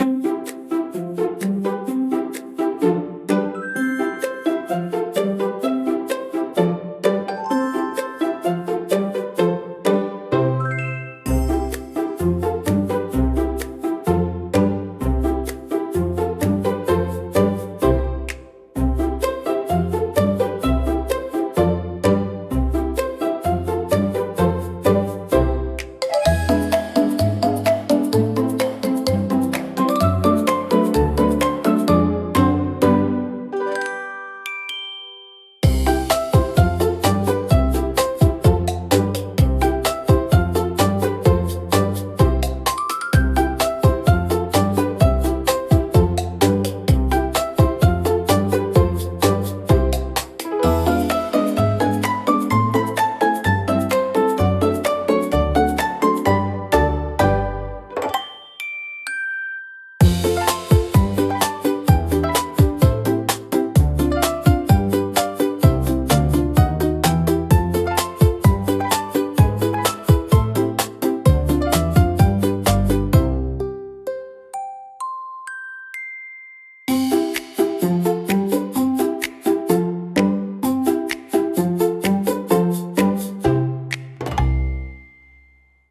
聴く人に安心感と温かさを届け、自然で可愛らしい空間を演出します。